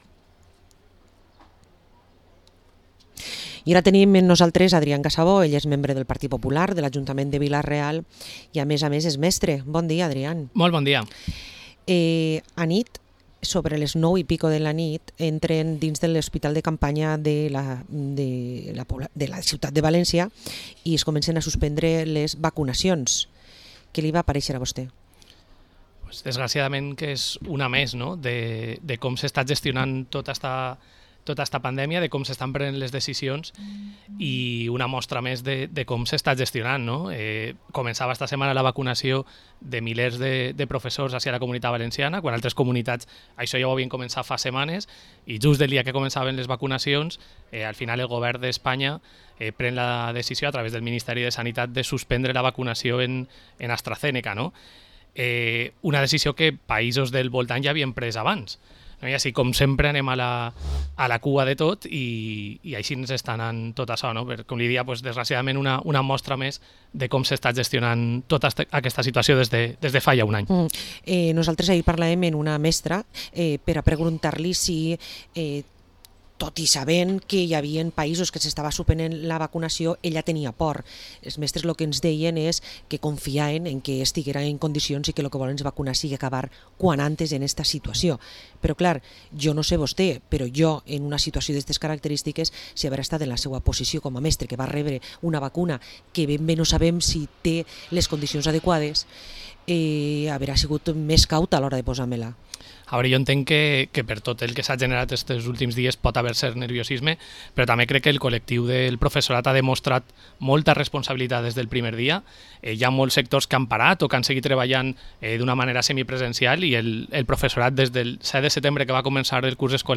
Entrevista al portavoz del PP de Vila-real, Adrián Casabó